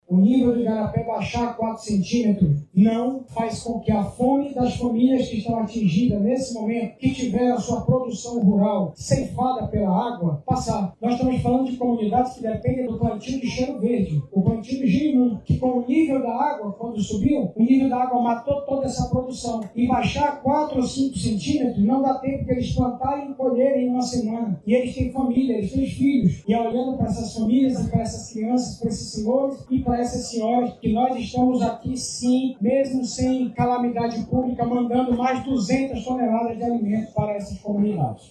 O prefeito de Manaus em Exercício, Renato Júnior, explica o objetivo da medida, mesmo com recuo do nível das águas, sinalizando o período do início da vazante dos rios.